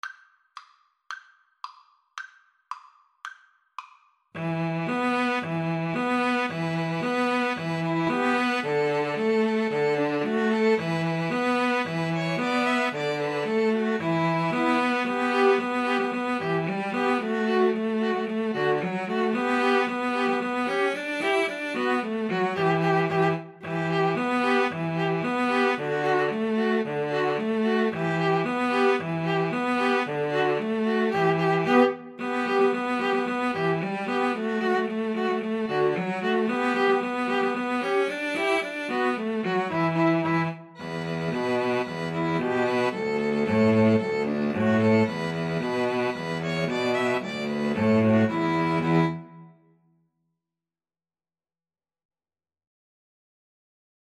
E minor (Sounding Pitch) (View more E minor Music for String trio )
Allegro moderato = c. 112 (View more music marked Allegro)
2/4 (View more 2/4 Music)
String trio  (View more Easy String trio Music)